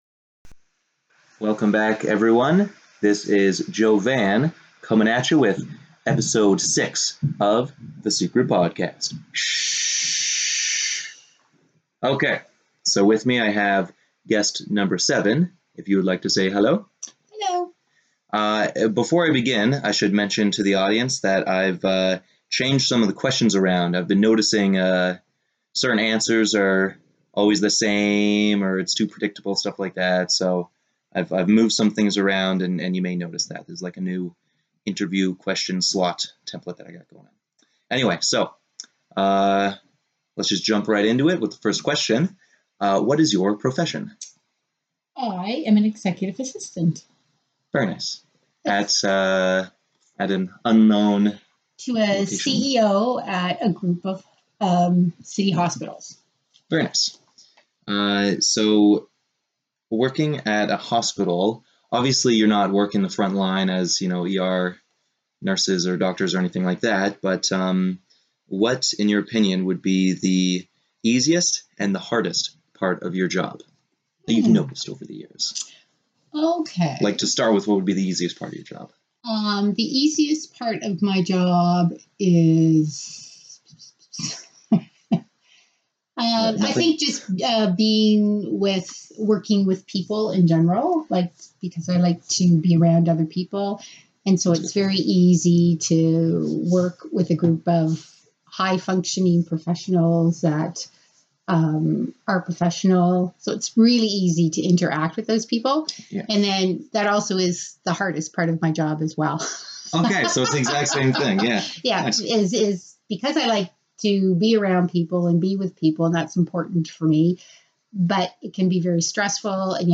Enjoy episode six of the secret podcast! Here I interview a woman I haven't known as long as the others but holds just as much weight in her character.